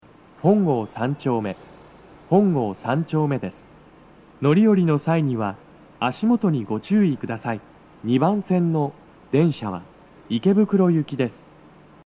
スピーカー種類 BOSE天井型
足元注意喚起放送が付帯されており、粘りが必要です。
2番線 池袋方面 到着放送 【男声